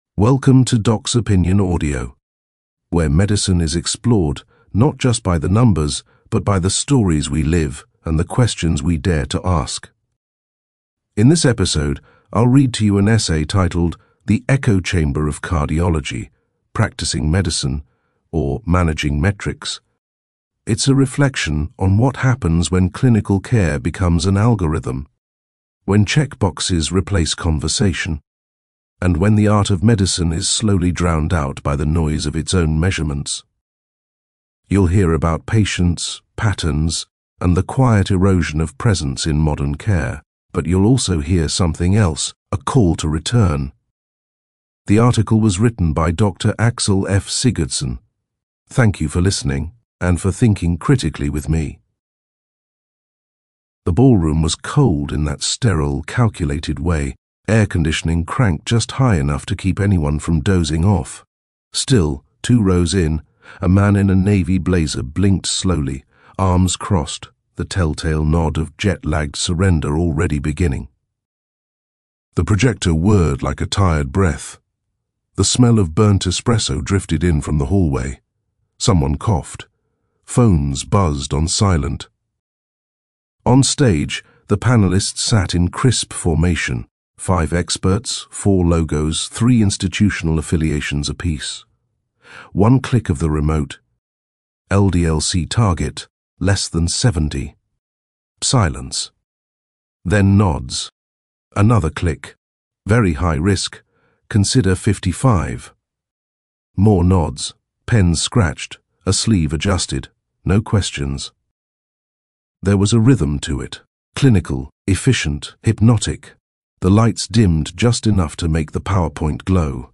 Share this post: Share on X (Twitter) Share on Facebook Share on LinkedIn Share on Email Estimated reading time: 10 minutes 🎧 Available in audio You can listen to this article — The Echo Chamber of Cardiology — narrated in full.